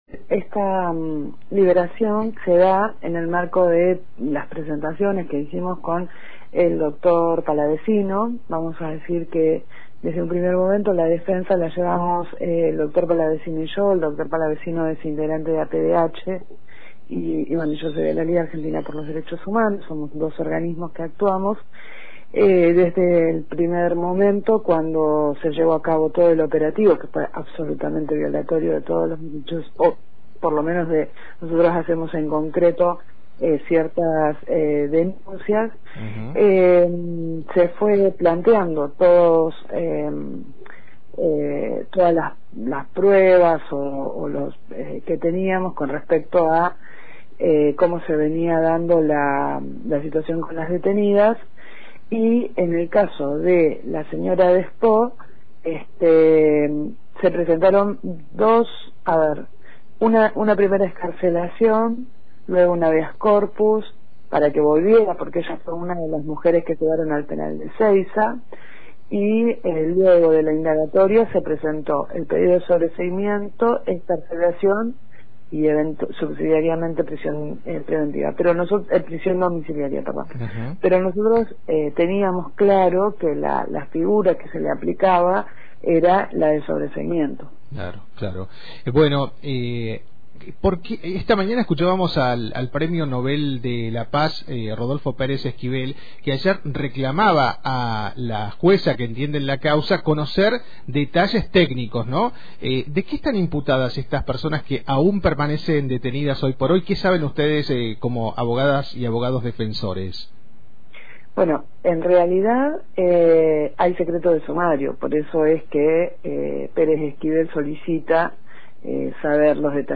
La defensa continúa con las presentaciones para la liberación y el sobreseimiento de todas las mujeres imputadas. Escuchá la entrevista completa